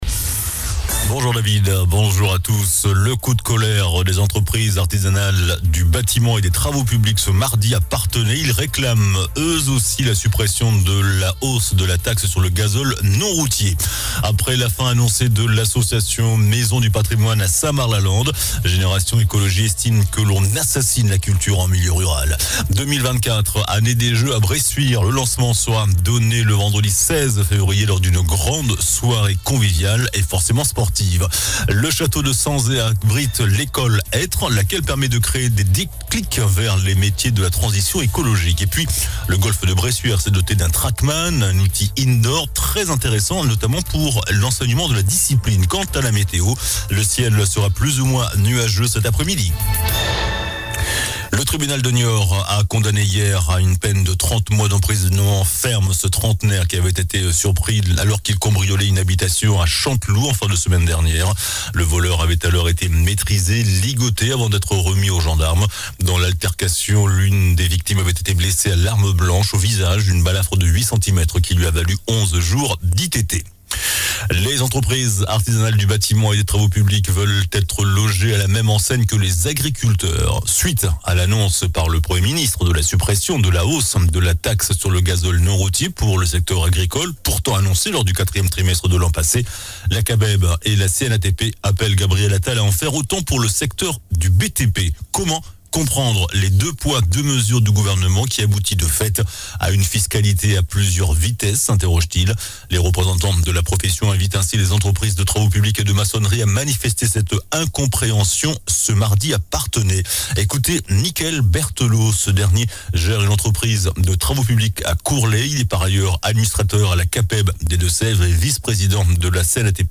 JOURNAL DU MARDI 06 FEVRIER ( MIDI )